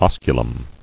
[os·cu·lum]